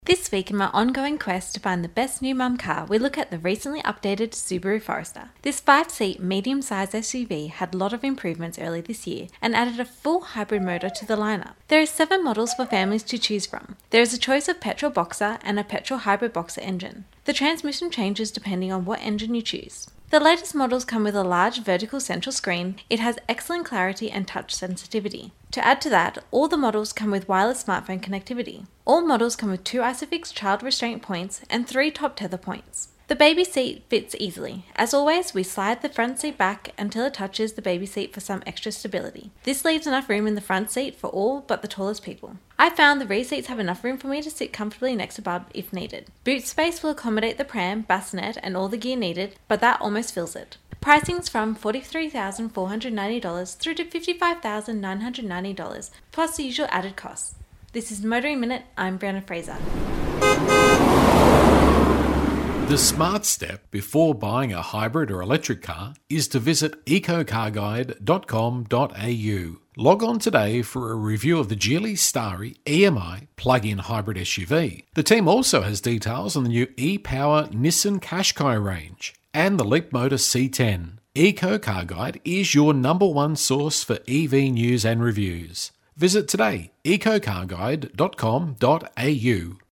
Motoring Minute is heard around Australia every day on over 120 radio channels.